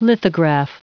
Prononciation du mot lithograph en anglais (fichier audio)